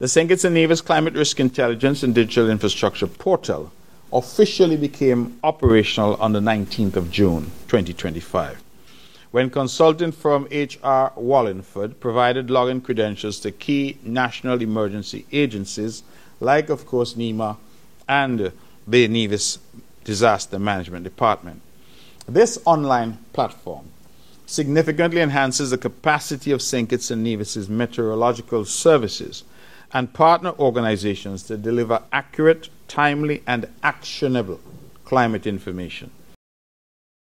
In this vein and in an effort to increase disaster preparedness in the federation, the St Kitts and Nevis Climate Risk and Digital Infrastructure (SKN-CRID) has been launched. Premier of Nevis, the Hon. Mark Brantley, gives more details: